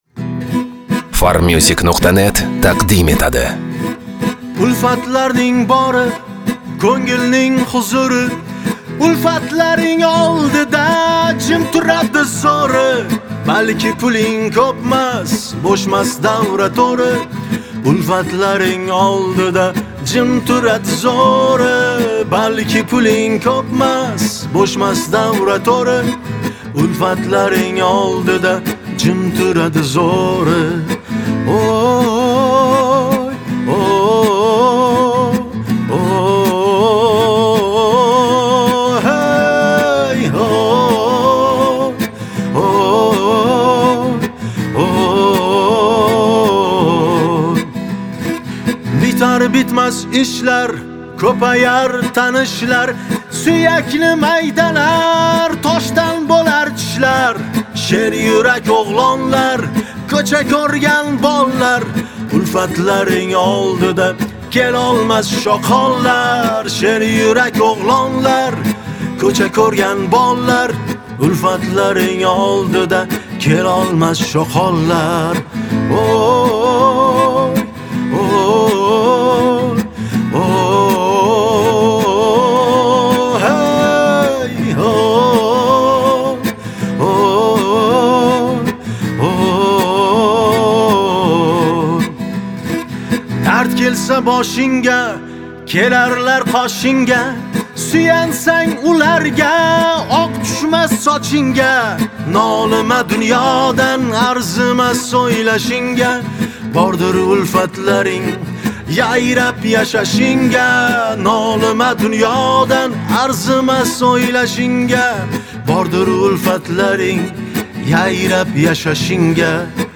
Узбекский песни